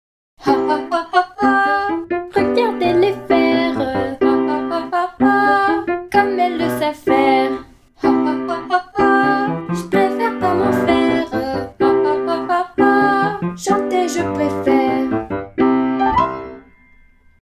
un conte musical pour enfants